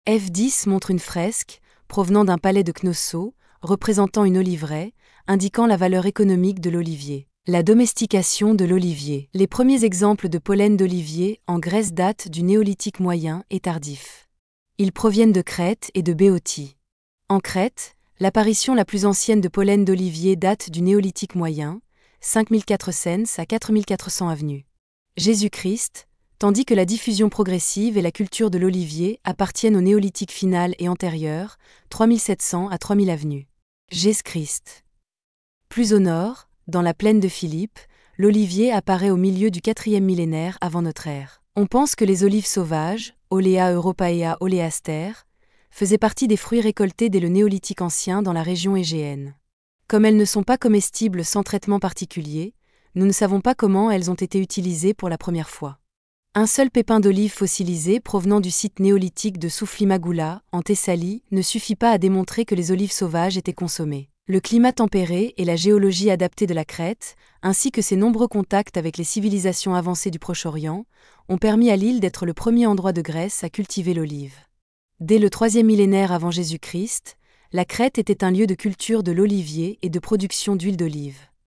Visite guidée audio